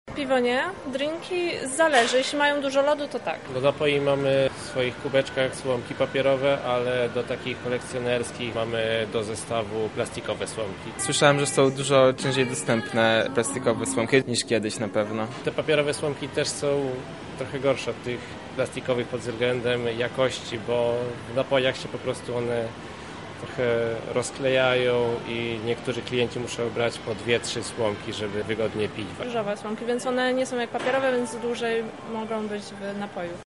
O tym jak wygląda to w Lublinie porozmawialiśmy z pracownikami tej branży: